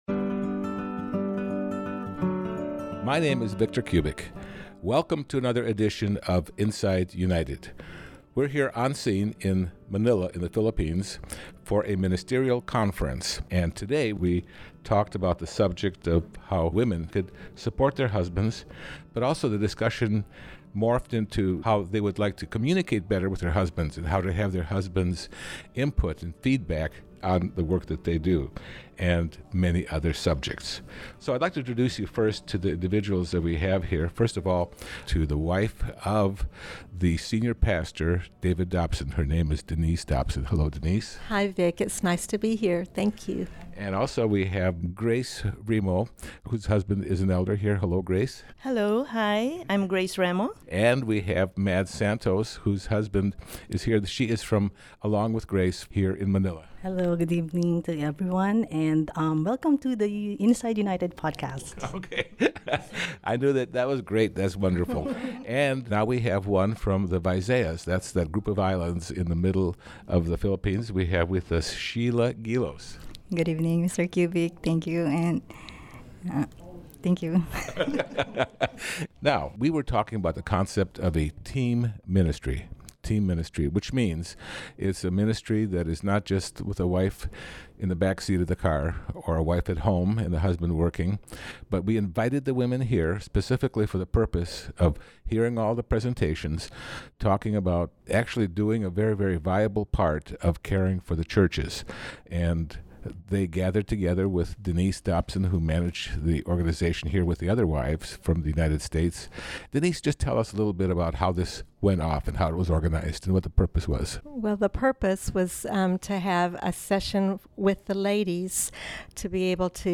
While in Manila, Philippines, for a ministerial conference
a group of wives attending the conference